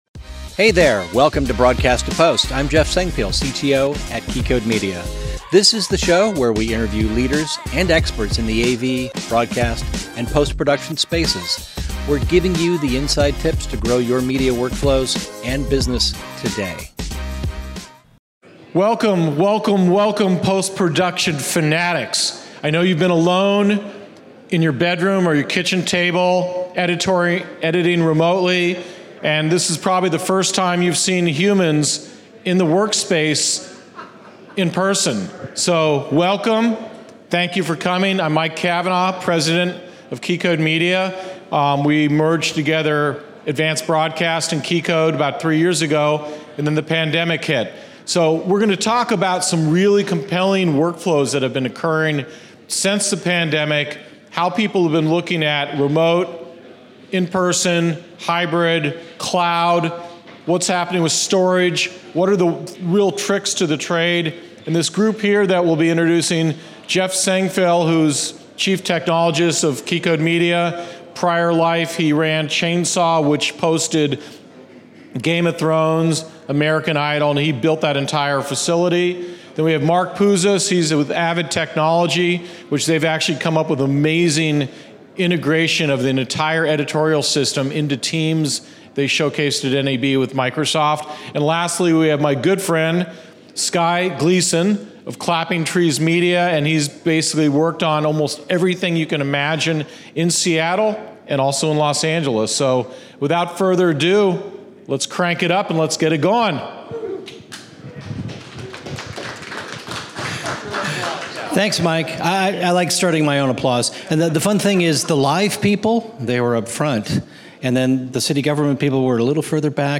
PostNAB-Seattle-State-of-Post-Production.mp3